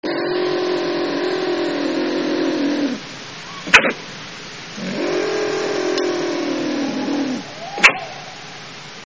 La fouine, Martes foina.
écoutez la fouine en colère!
Elle mène grand tapage à l'émancipation des jeunes en début d'été et lors de joutes territoriales: son cri ressemble à un aboiement suraigu.
fouine.mp3